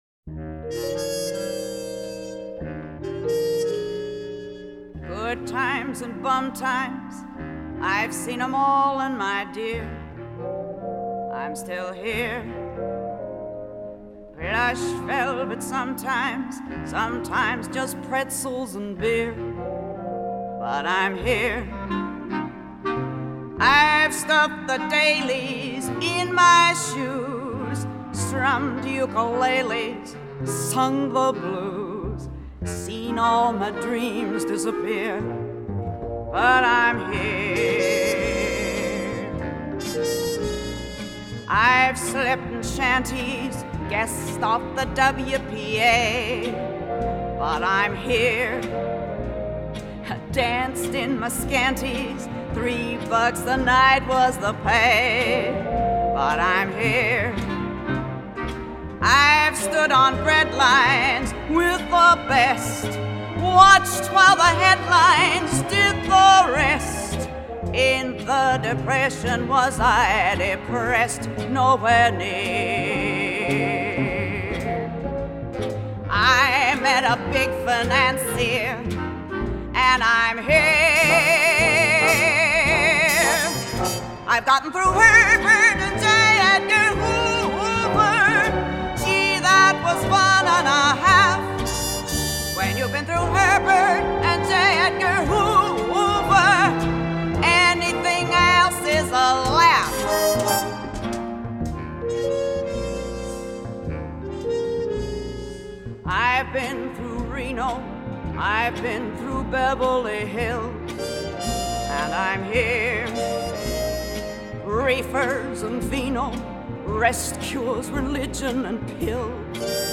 1971   Genre: Musical   Artist